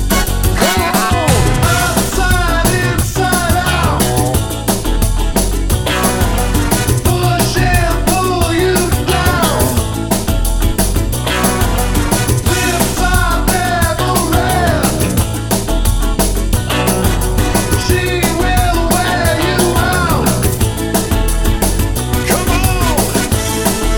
Two Semitones Down Pop (1990s) 4:02 Buy £1.50